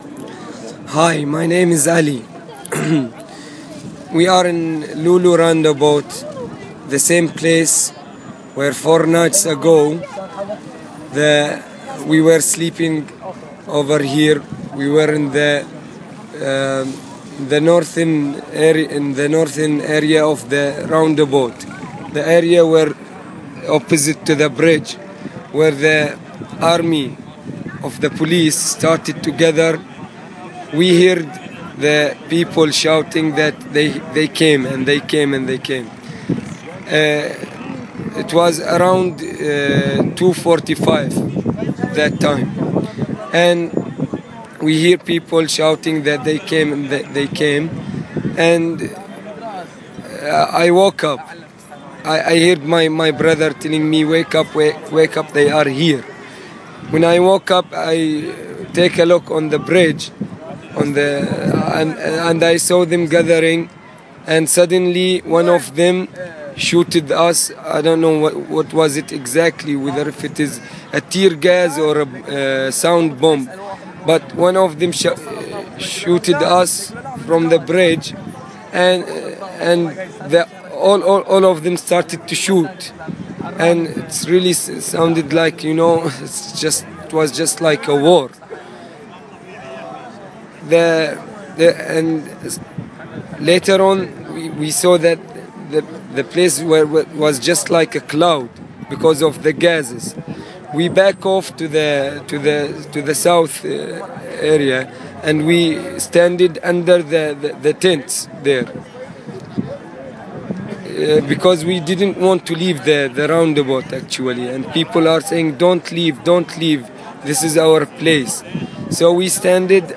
eyewitness acct of Pearl raid